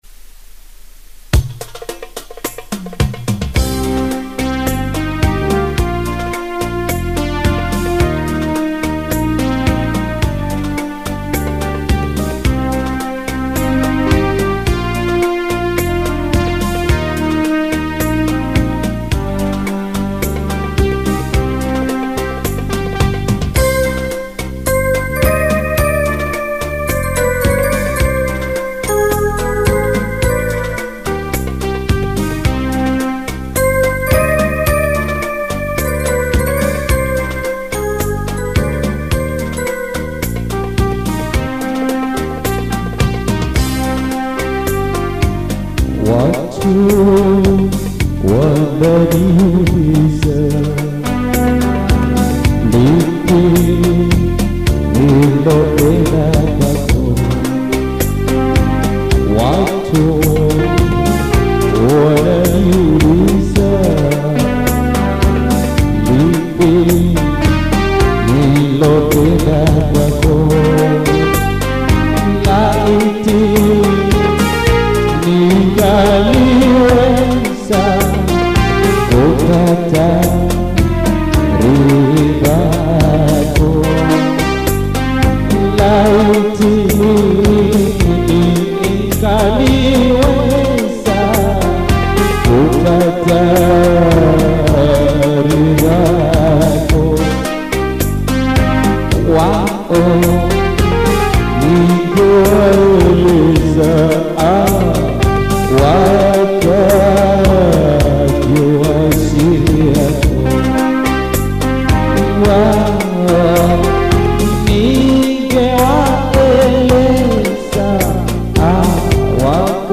In this soulful track
emotive vocals